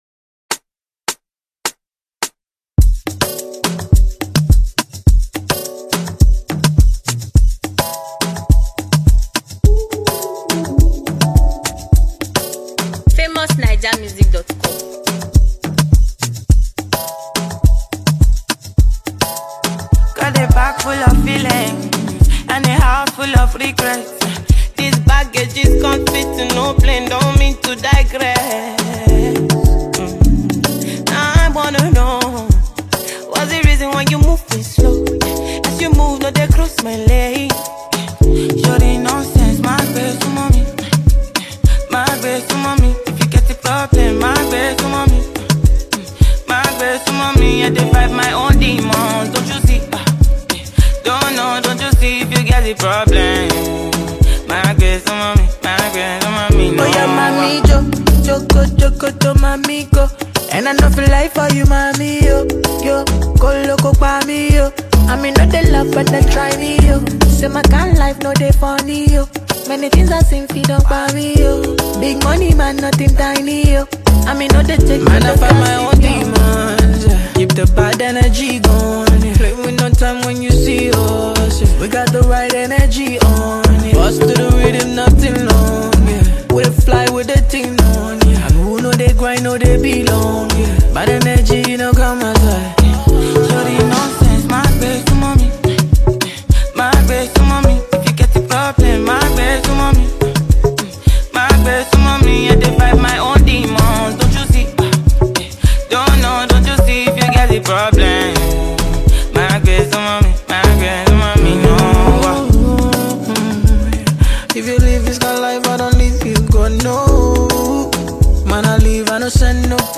” a shiny new, lovely tune.